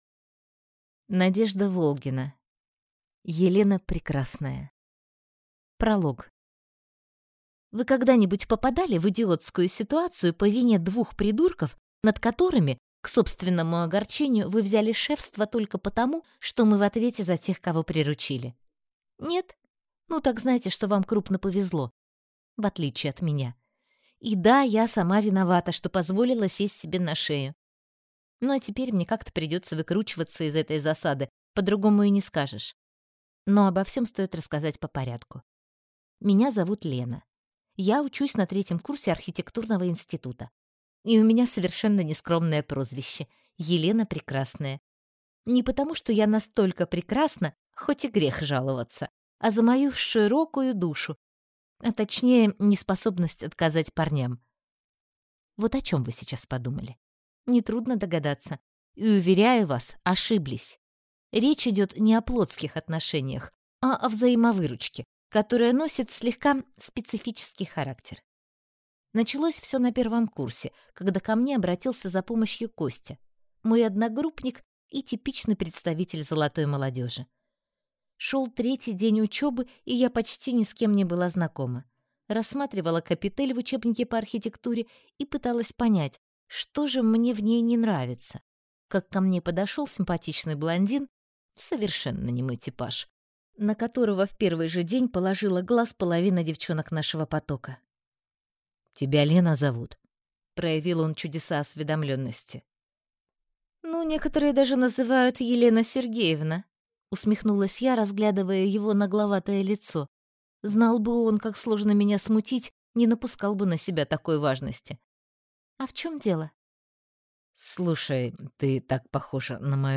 Аудиокнига Елена Прекрасная | Библиотека аудиокниг